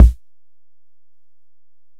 Kick (3).wav